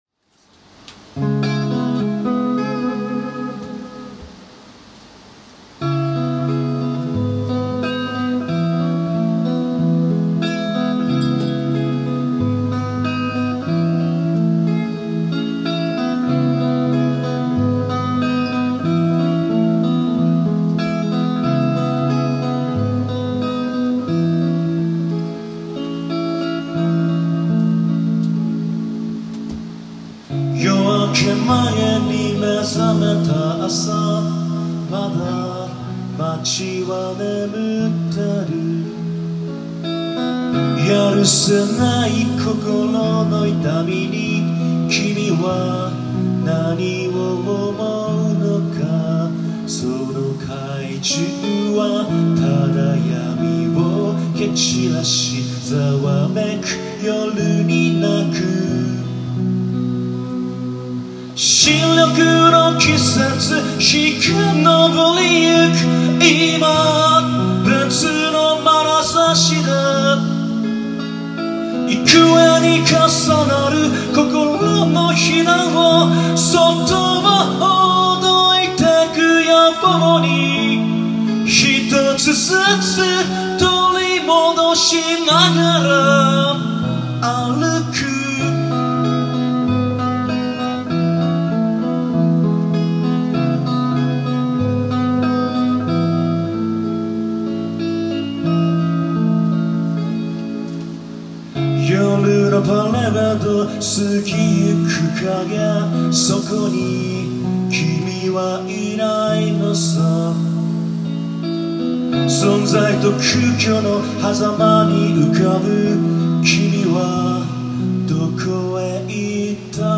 ※ラウン試聴音源
☆2025.7.2 [WED] at lown, 下北沢
弾き語りワンマン
E.guitar